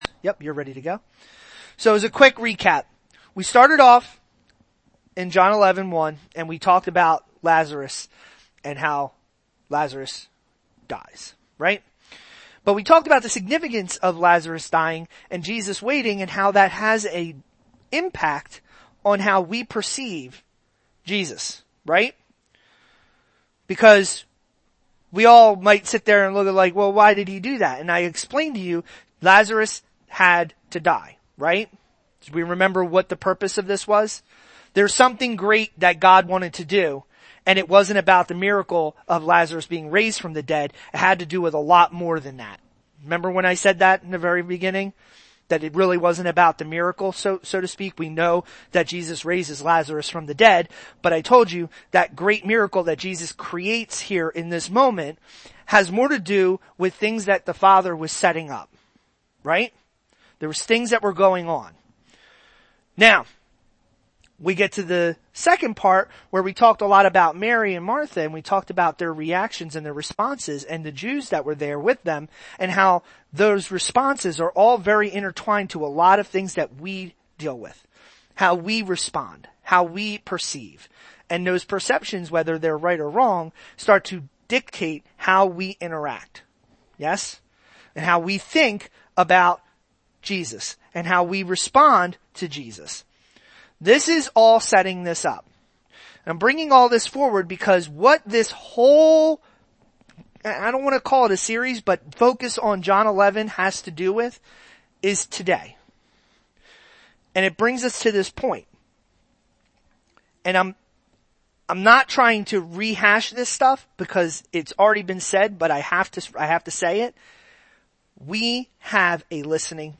John 11:45-57 Service Type: Sunday Pay attention and listen!